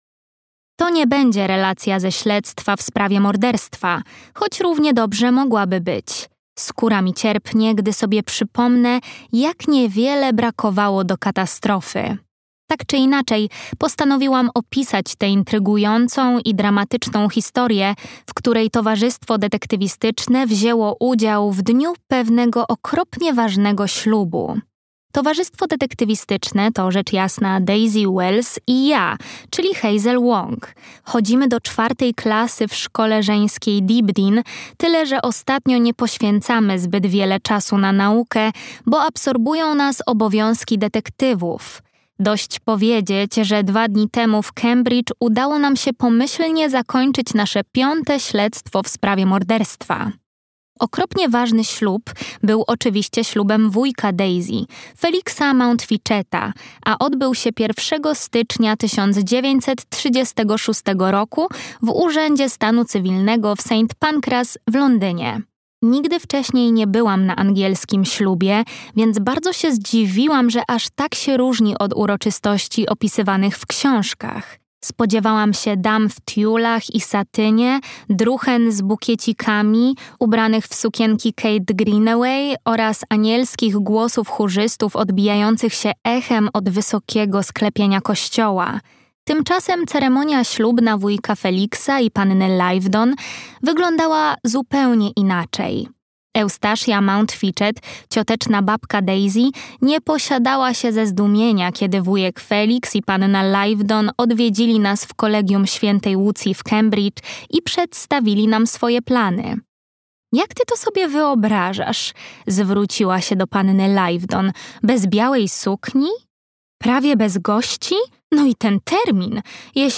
Była sobie zbrodnia. Zbrodnia niezbyt elegancka - Robin Stevens - audiobook